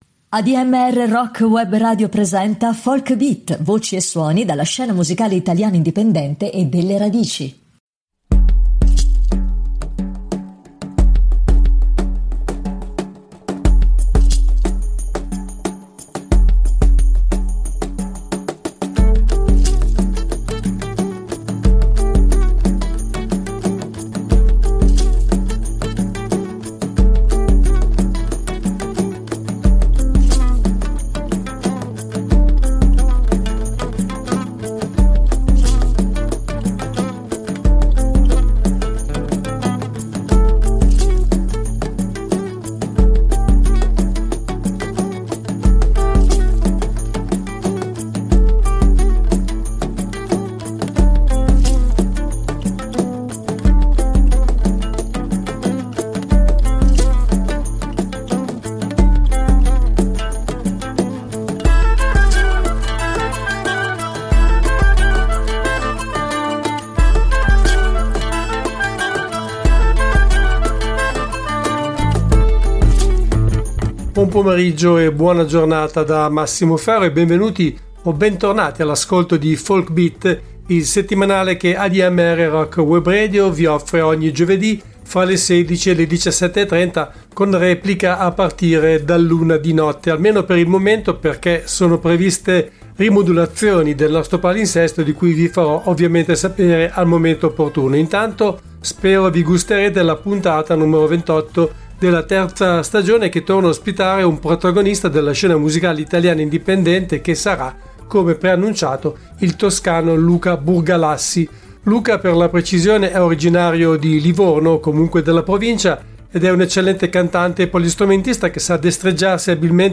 Part I: �Folk Beat� (11.04.2024) Ospite del programma al telefono